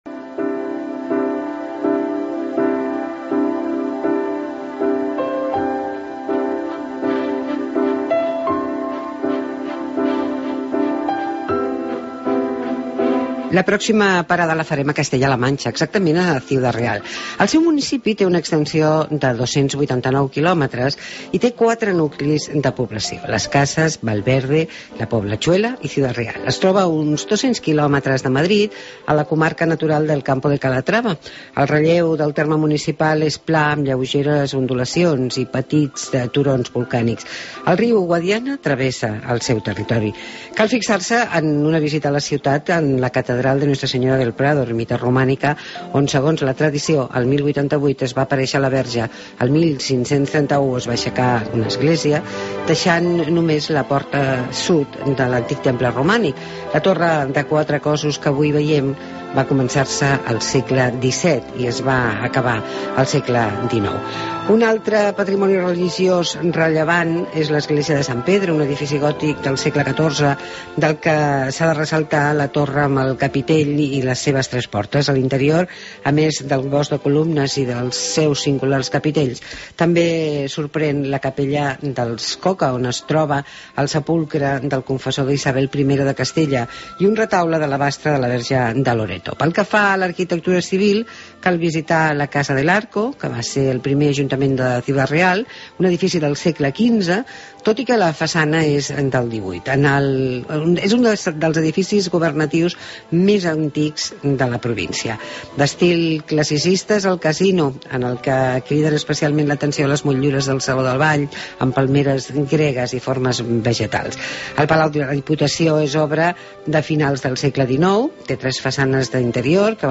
Entrevista a Nemesio de Lara Gutiérrez, presidente de la Diputación de Ciudad Real i de Finavin